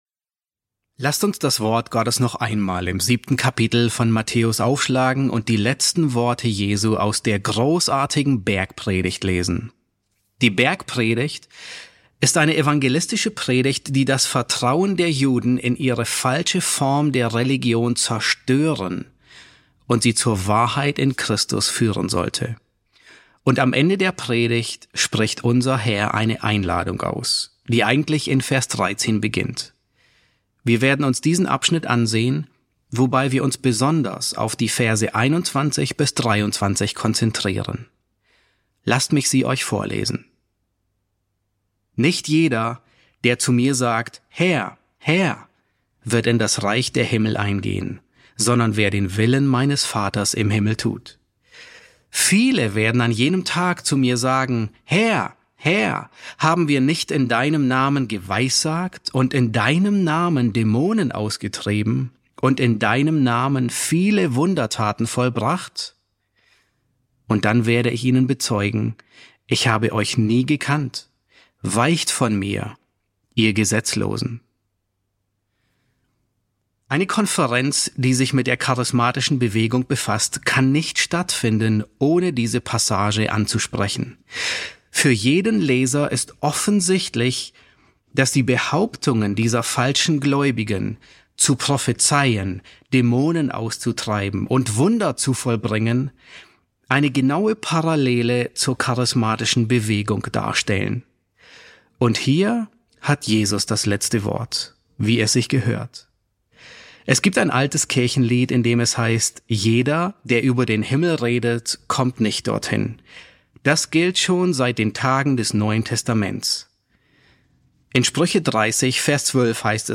Predigten auf Deutsch